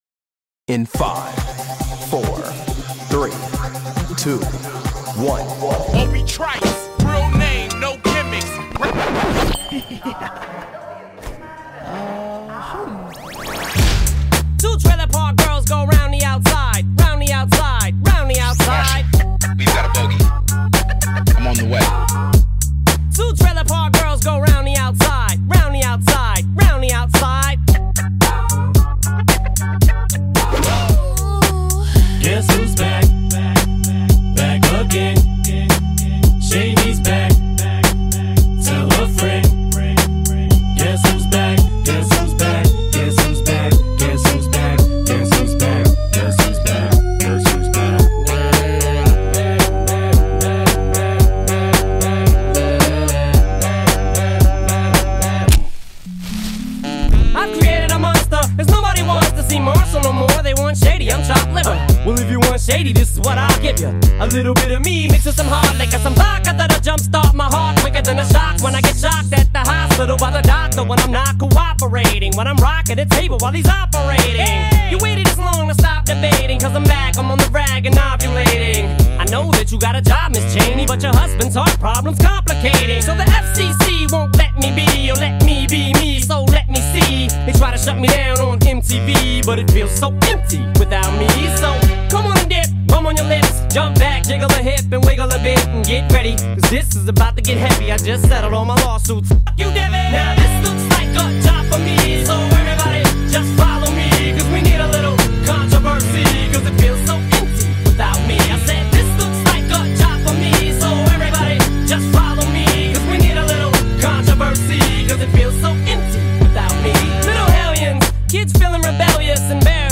Famous american song.